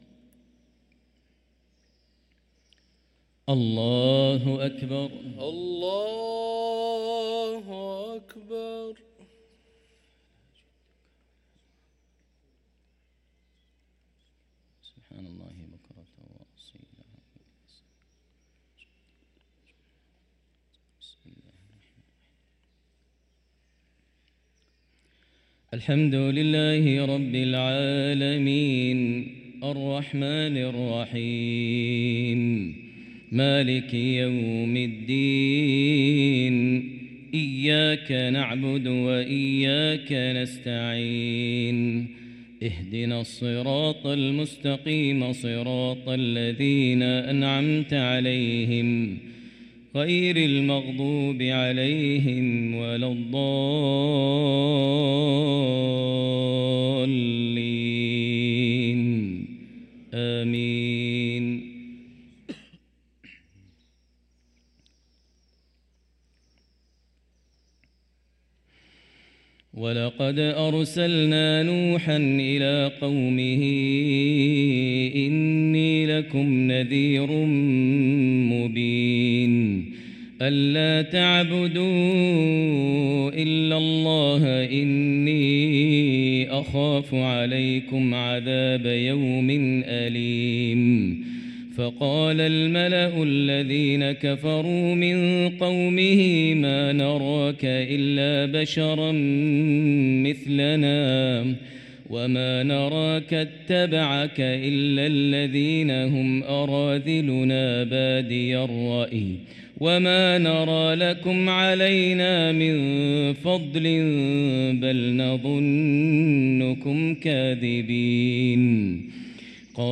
صلاة الفجر للقارئ ماهر المعيقلي 19 رجب 1445 هـ
تِلَاوَات الْحَرَمَيْن .